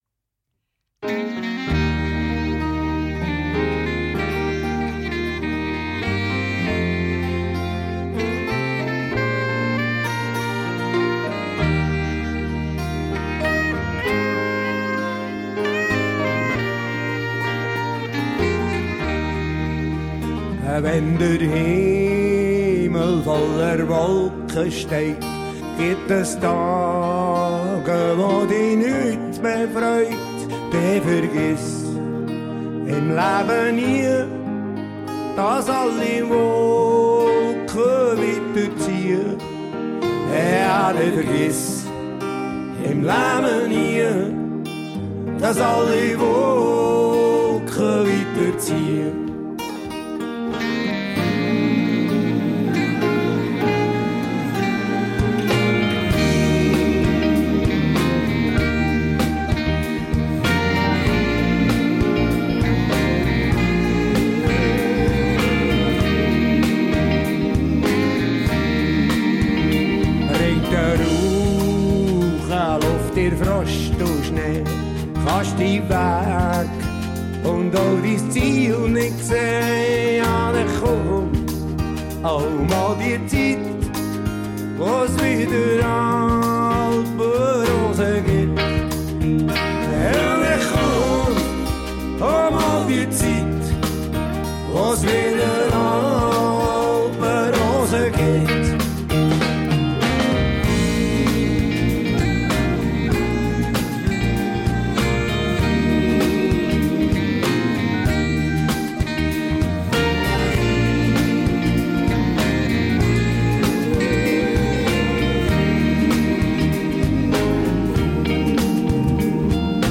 Bibelgespräch